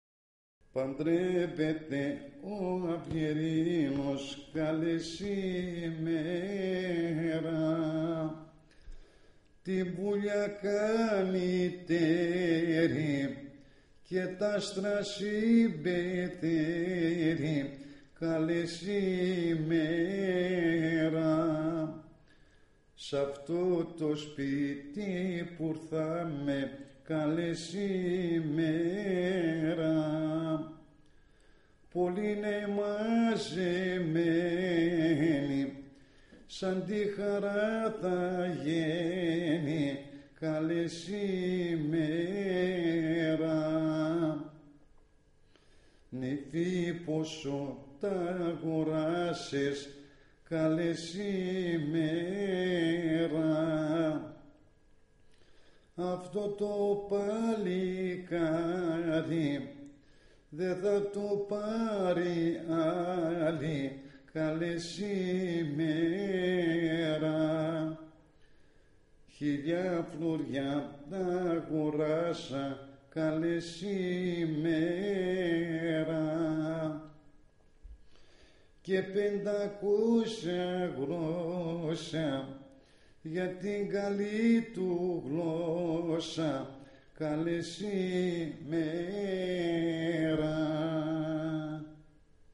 Αφού τελείωνε και αυτό στην συνέχεια τραγουδούσαν και χόρευαν πάλι όλοι οι συγγενείς μαζί (
Track 2, στο cd της επιτόπιας έρευνας).